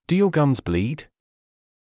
ﾄﾞｩｰ ﾕｱ ｶﾞﾑｽﾞ ﾌﾞﾘｰﾄﾞ